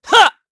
Siegfried-Vox_Attack2_kr_b.wav